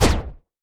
etfx_explosion_plasma2.wav